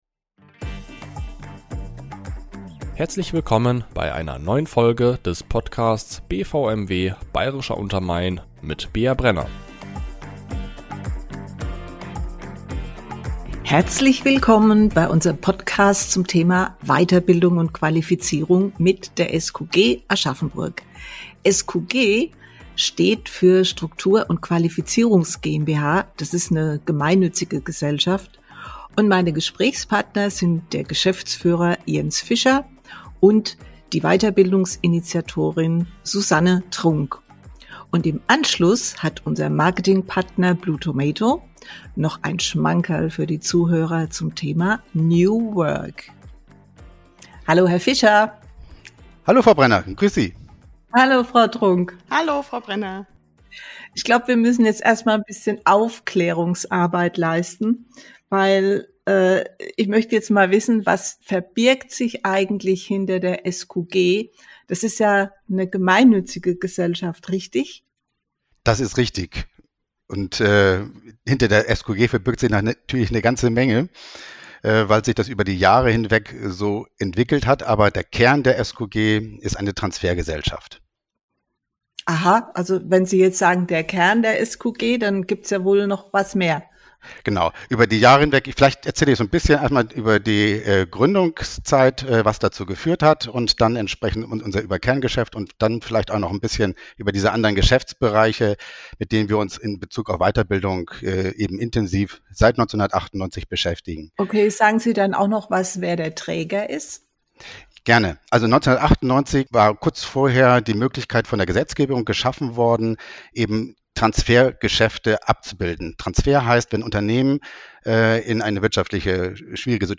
Diese Folge unterstützt unser Marketing-Partner Blue Tomato und hat am Ende des Interviews ein limitiertes Angebot für euch zu einem kostenlosen Live Online Workshop zum Thema "New Work".